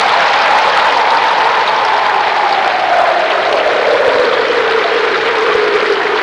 Wind And Rain Sound Effect
Download a high-quality wind and rain sound effect.
wind-and-rain.mp3